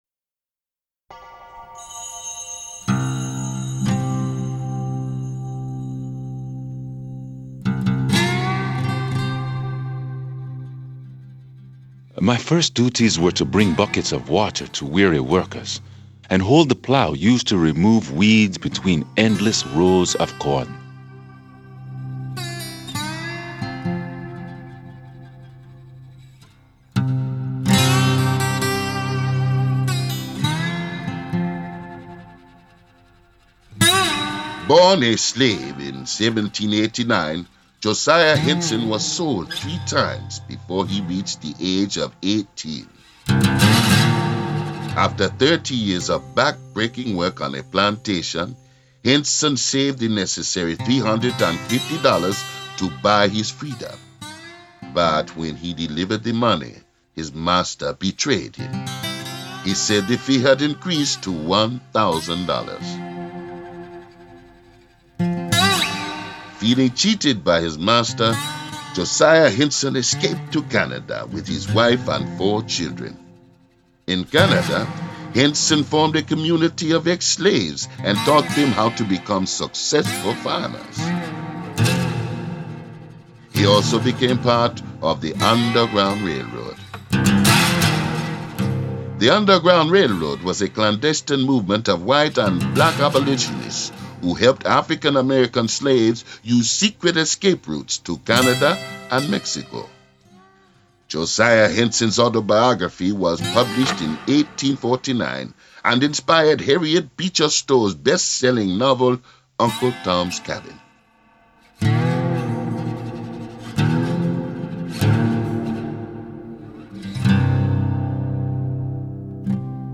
Narrators: